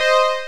emergency.wav